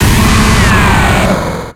Cri de Steelix dans Pokémon X et Y.